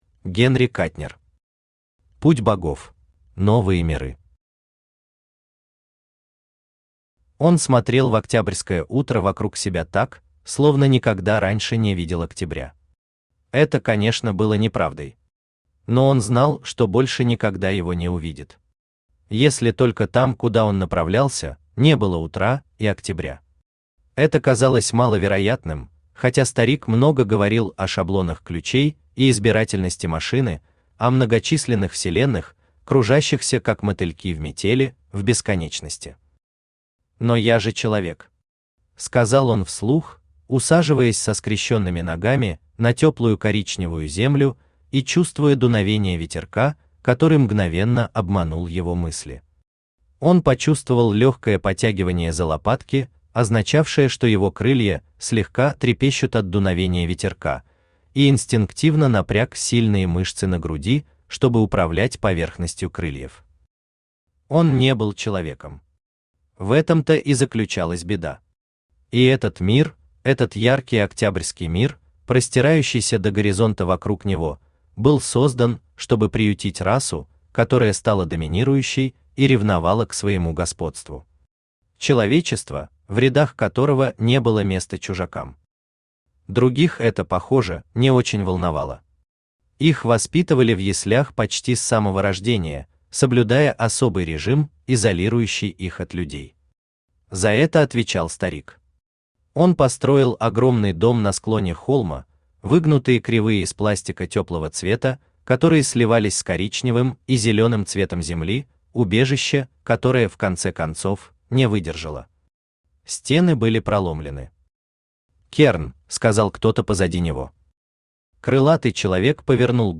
Aудиокнига Путь богов Автор Генри Катнер Читает аудиокнигу Авточтец ЛитРес.